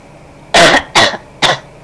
Coughing Sound Effect
Download a high-quality coughing sound effect.
coughing-1.mp3